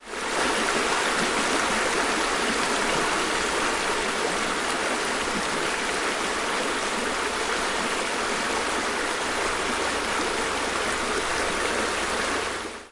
Water streams » Water stream 1
描述：One in a series of smaller water falls from a stream in the woods. Water is pouring down in between some rocks.
标签： stream fieldrecording waterfall unprocessed Water
声道立体声